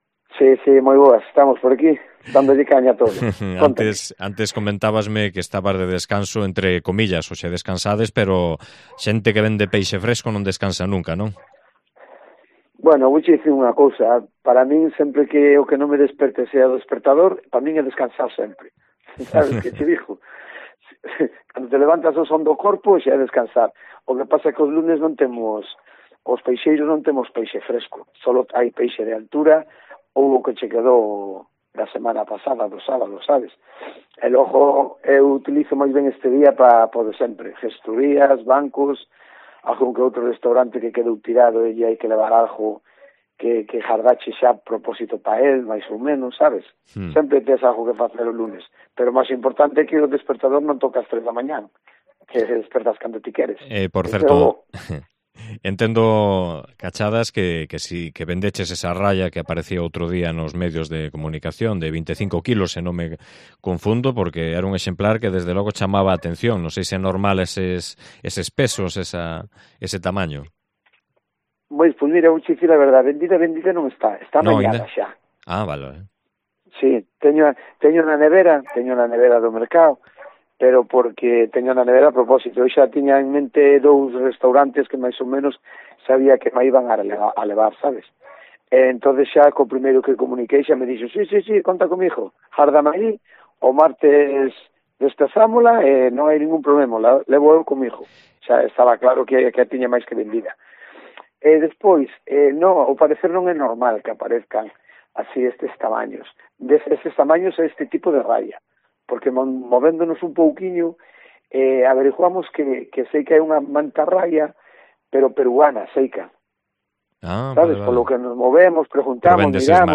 MERCADO EN BARCELOS Entrevista